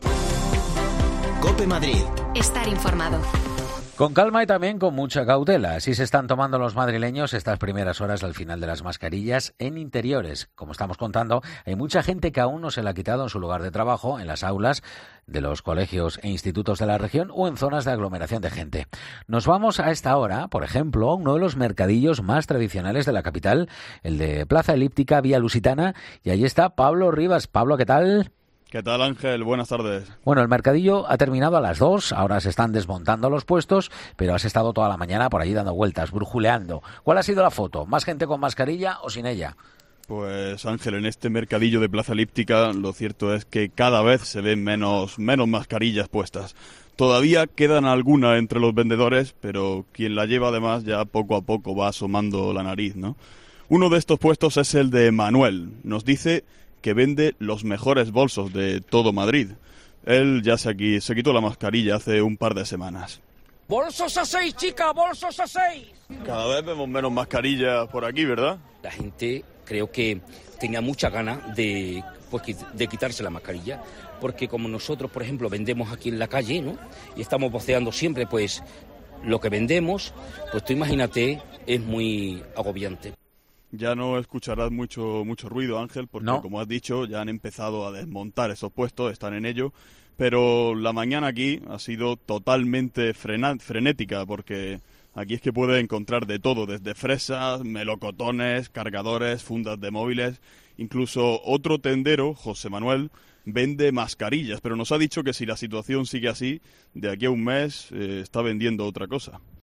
En el mercadillo de Plaza Elíptica-Vía Lusitana, uno de los más tradicionales de la capital lo cierto es que cada vez se ven menos mascarillas puestas.
La actividad en este mercado es frenética porque literalmente es que hay de todo.